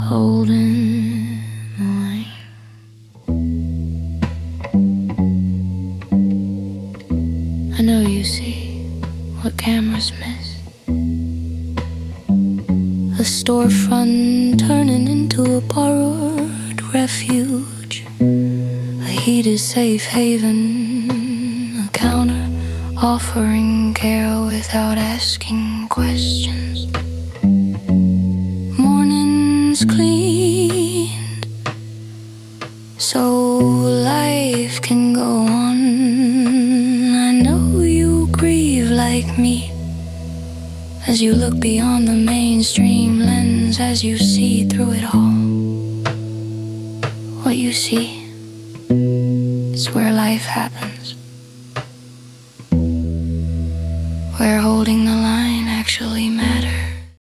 The soundscape and visuals were created with AI.